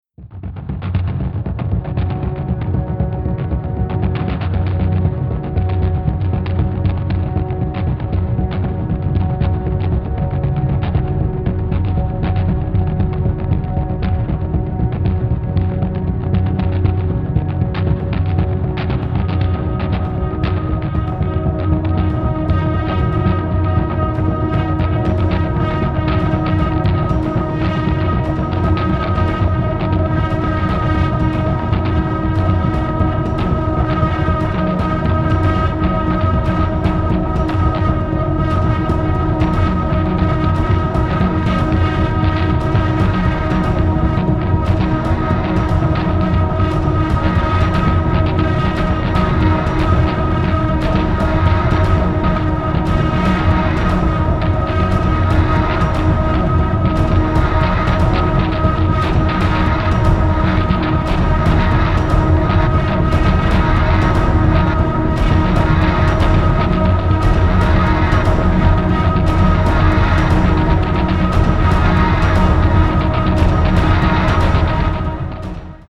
AMBIENT/DOWNTEMPO